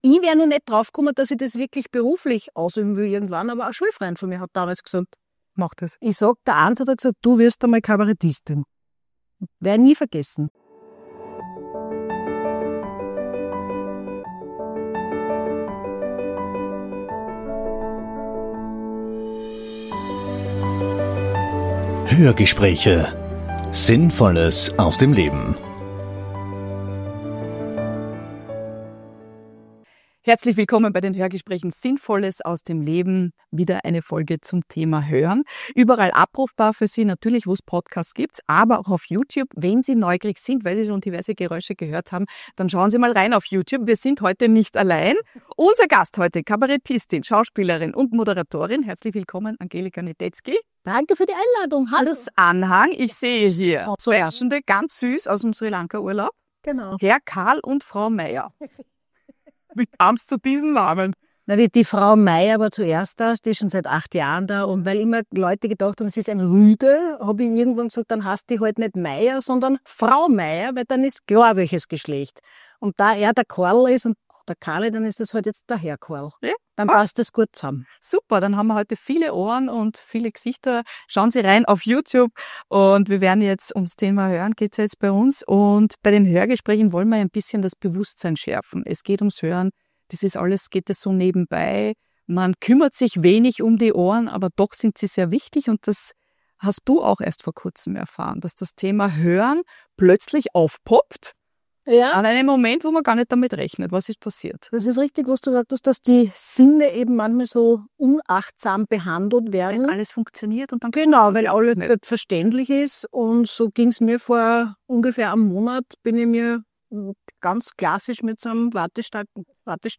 Aber auch über das Hören hat Angelika Niedetzky was zu sagen: warum sie künftig noch besser auf ihre beiden Ohren aufpassen wird und wie es eigentlich ihrem Freund mit Cochlea-Implantaten geht. Freuen Sie sich auf ein lustiges, sehr ehrliches Gespräch mit einer großartigen österreichischen Komödiantin, die mit ihren beiden Begleitern, Frau Mayer und Herrn Karl gleich für ein paar Lacher mehr im Studio gesorgt hat.